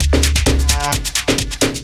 Percasynth-44S.wav